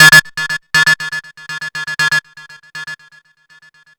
TSNRG2 Lead 012.wav